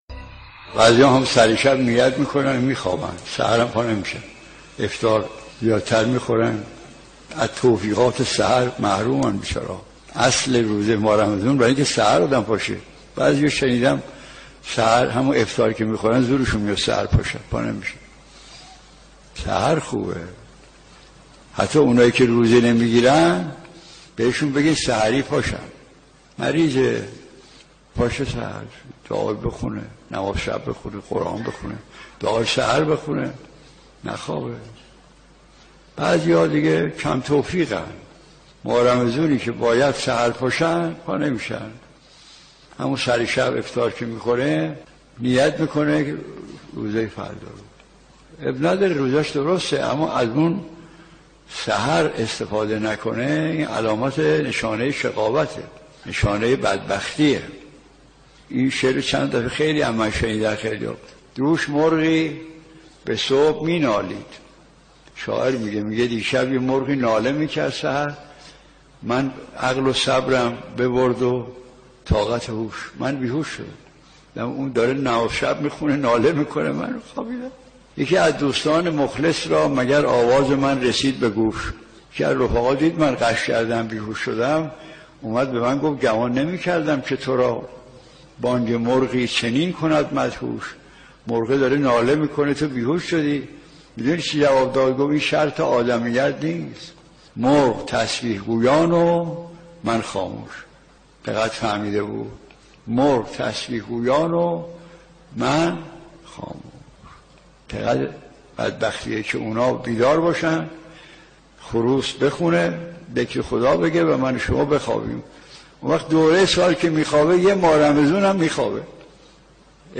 مرحوم آیت‌الله مجتهدی تهرانی، روایاتی درباره اهمیت سحرخیزی بندگان در ماه مبارک رمضان می‌گوید.